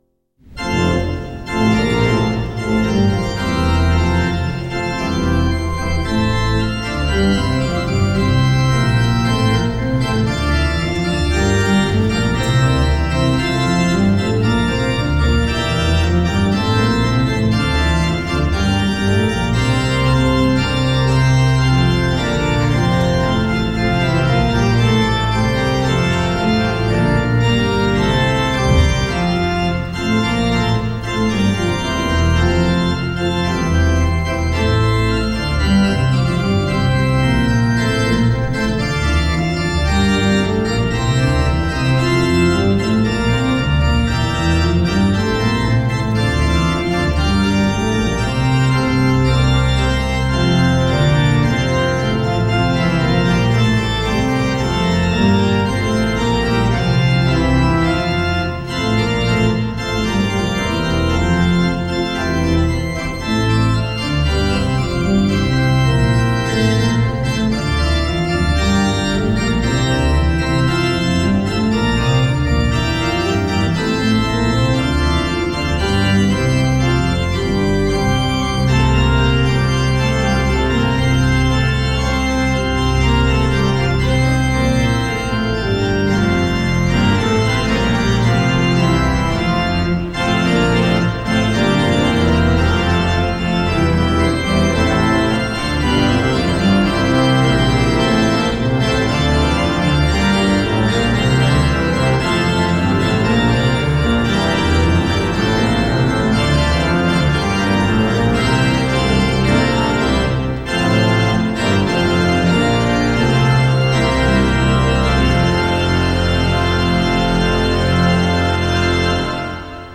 1 [Prédication] Lundi 10 février 2025 : Mc VI, 53-56